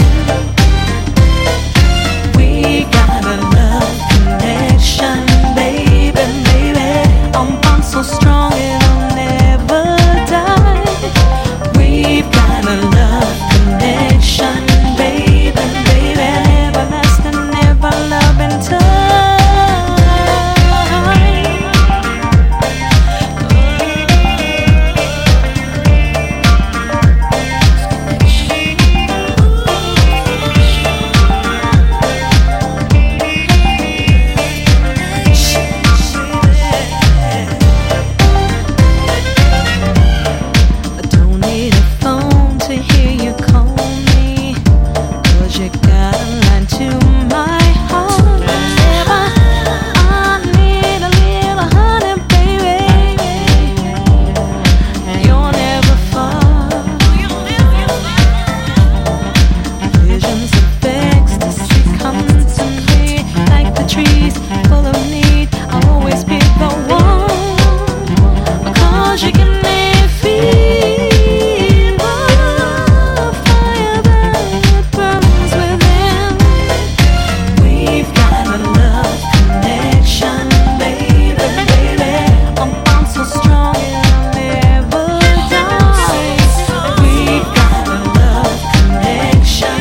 JAPANESE HOUSE / HOUSE / CLUB JAZZ
クラブ・ジャズ好きも必聴の生音ハウス！